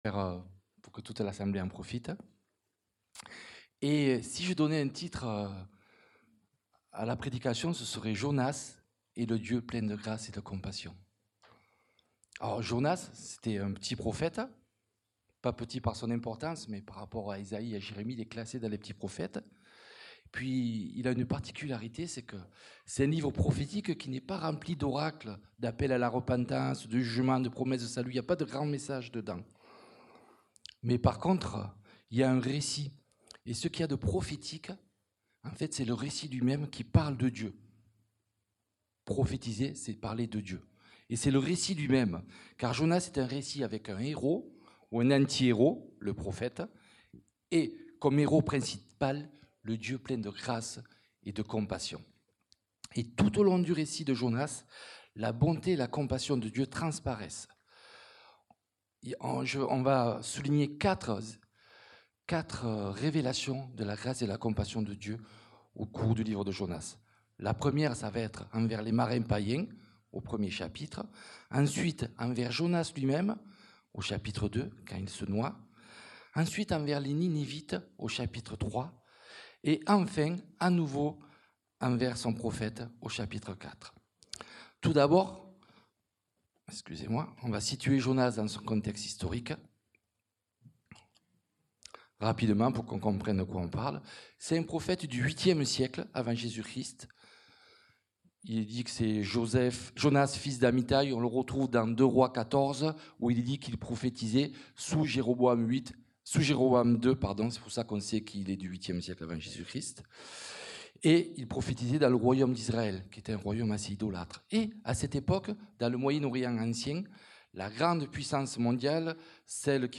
Culte du dimanche 13 juillet 2025